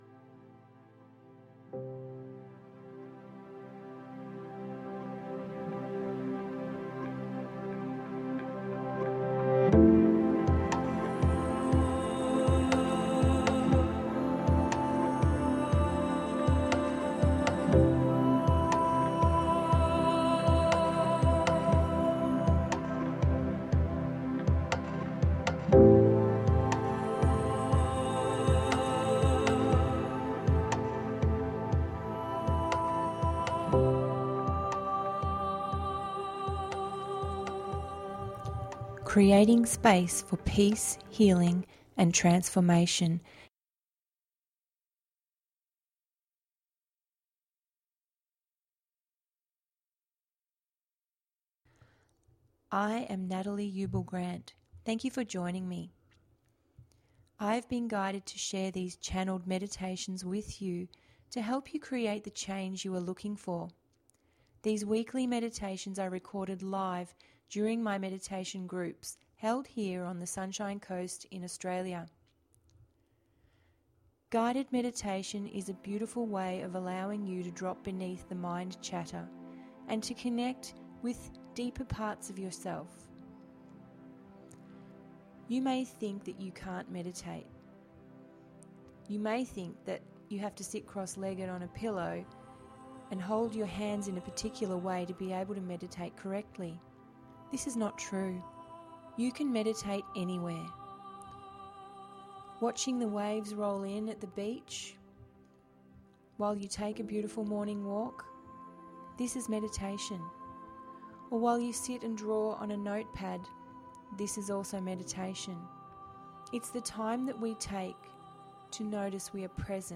Full moon and the doorway to your future…082 – GUIDED MEDITATION PODCAST